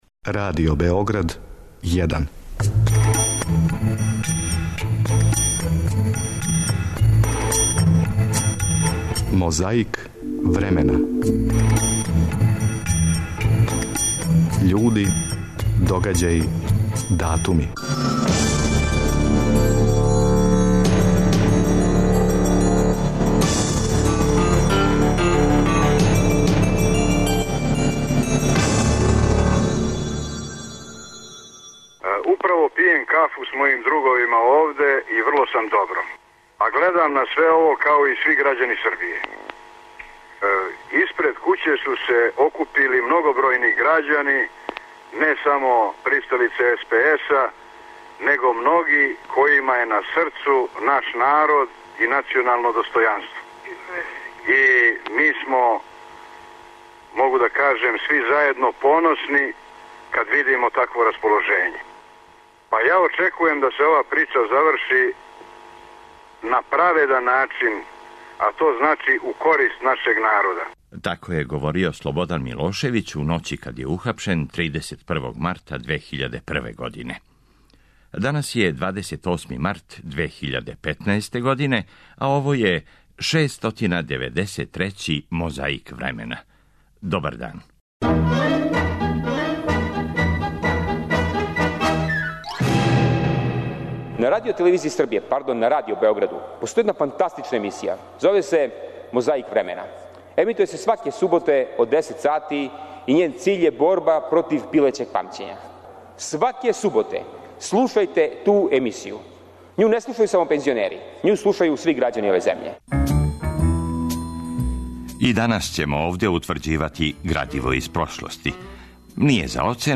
С обзиром на значај, Радио Београд 1 преносио је догађај директно.
Подсећа на прошлост (културну, историјску, политичку, спортску и сваку другу) уз помоћ материјала из Тонског архива, Документације и библиотеке Радио Београда.